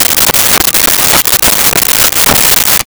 Saw Wood 05
Saw Wood 05.wav